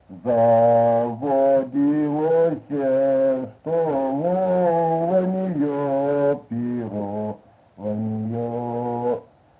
Произнесение частицы –ся в возвратных формах глаголов как –се